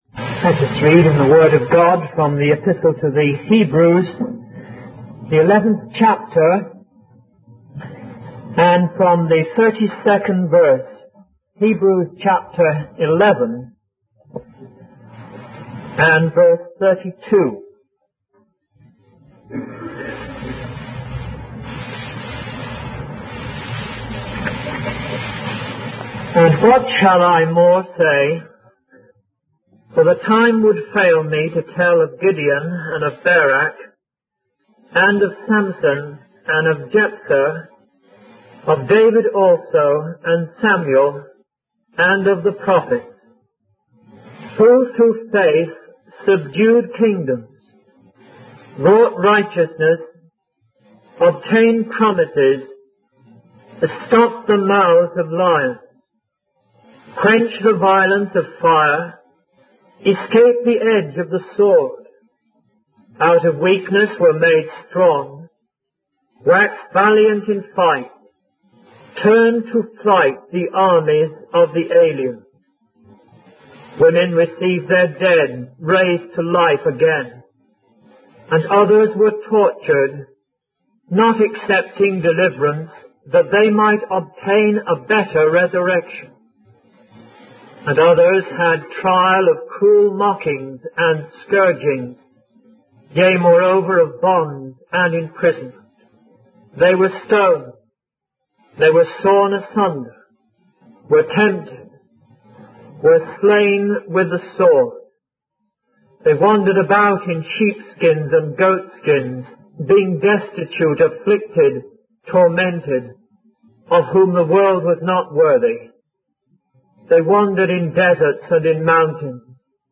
In this sermon, the preacher focuses on the importance of faith and the examples of faith found in the Bible.